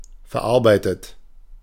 Ääntäminen